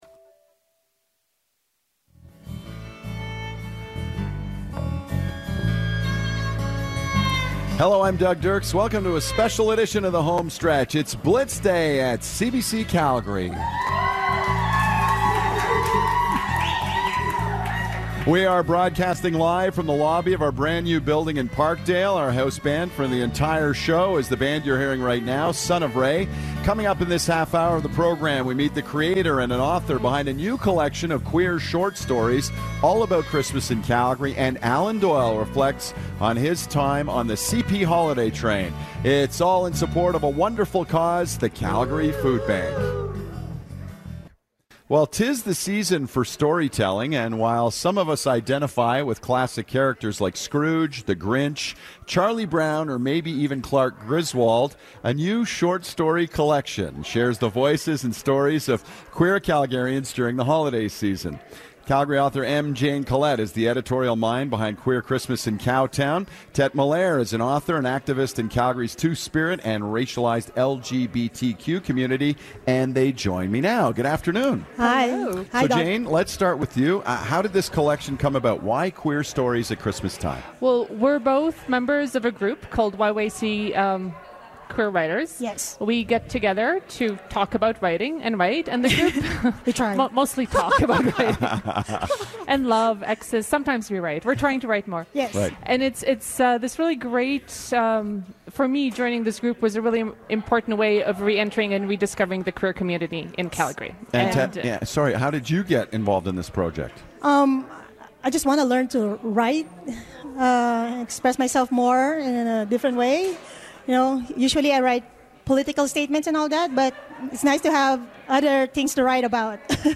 Here’s a clip from our interview: